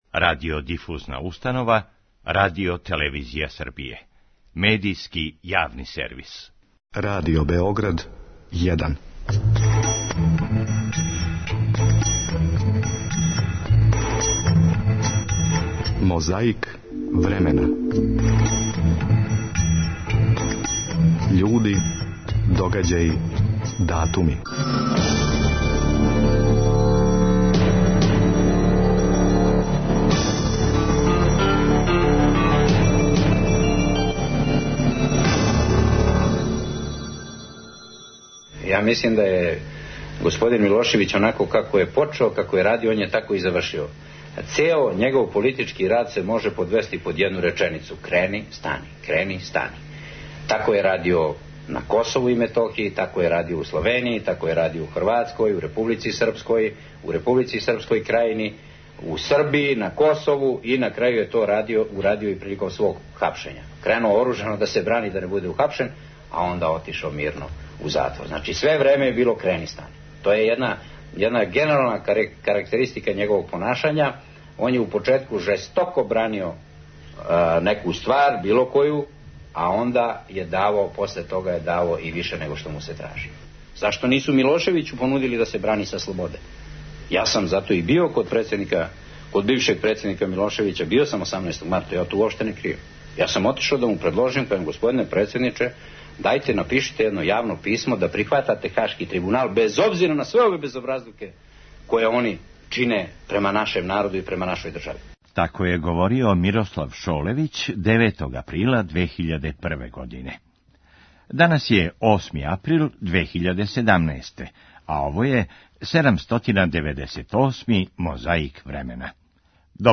Том приликом одржао је мису на стадиону Кошево пред више од 40 000 верника.
Изјаве неких од њих, уз изјаву спонтано присутног функционера СПС-а Бранислава Ивковића.